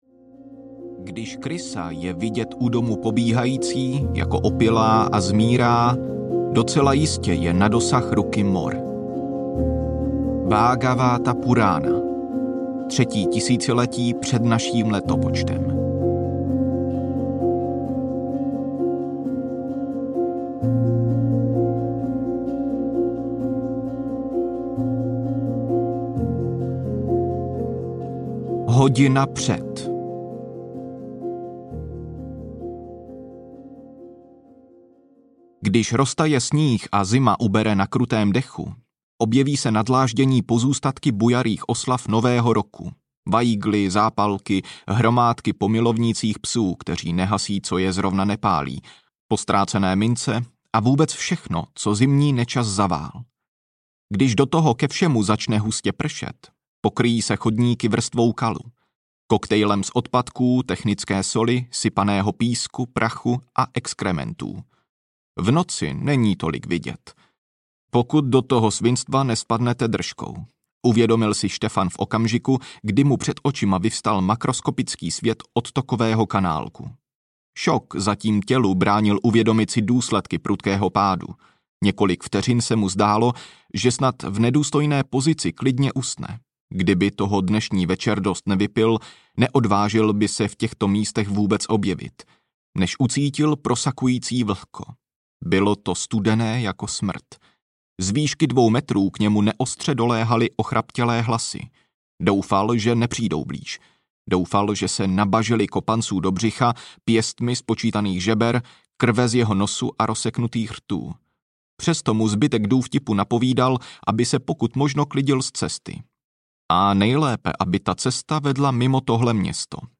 Umrlčí tanec audiokniha
Ukázka z knihy